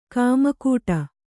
♪ kāmakūṭa